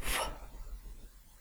wh